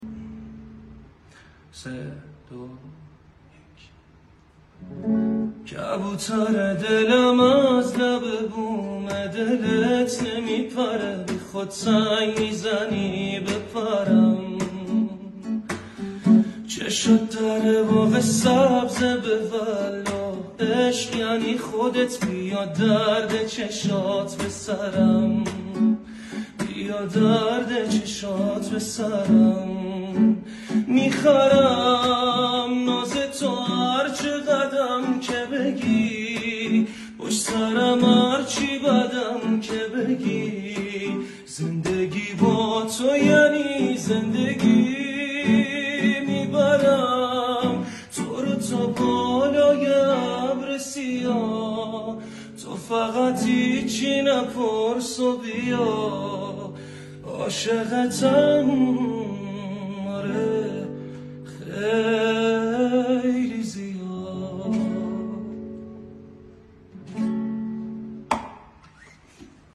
دانلود نسخه گیتار :